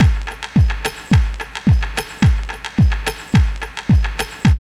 09.1 LOOP2.wav